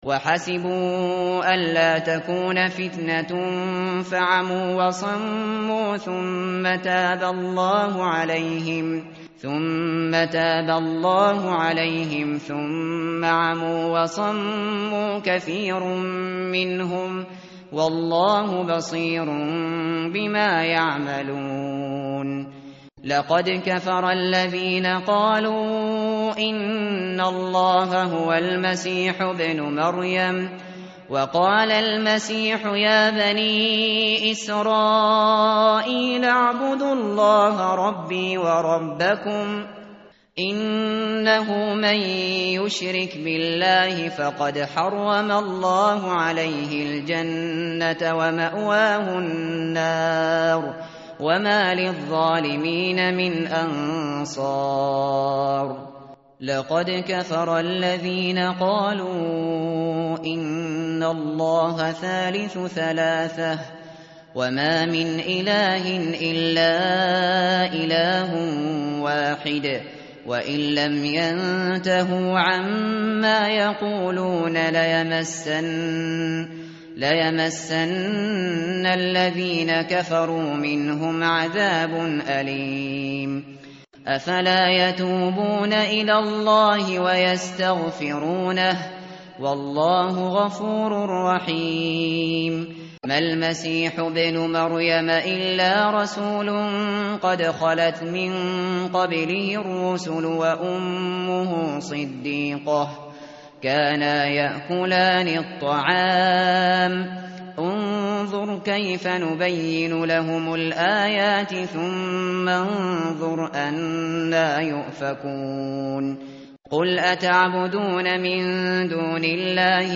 tartil_shateri_page_120.mp3